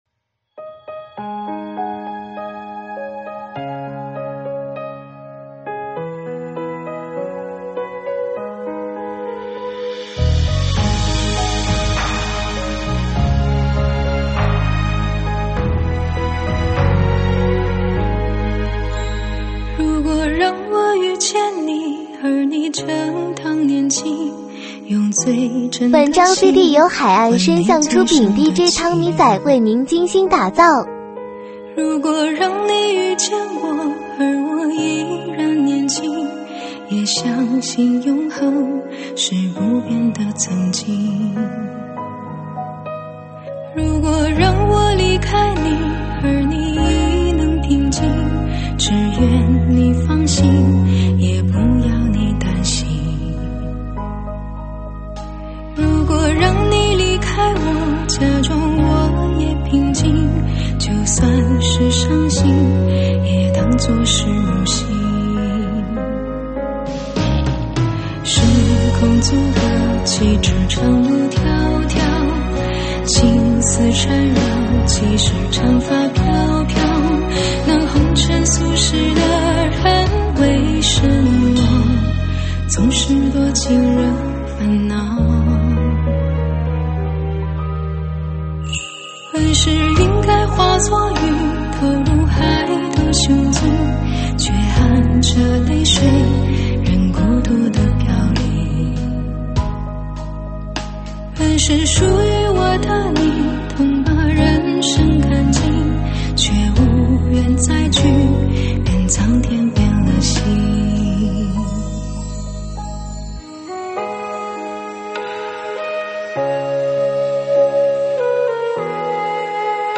舞曲编号：81299